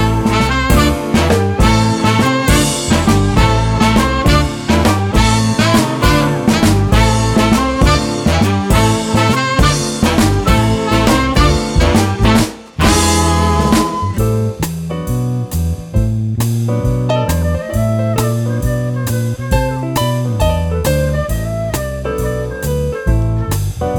No Saxophone Solo Pop (1960s) 4:33 Buy £1.50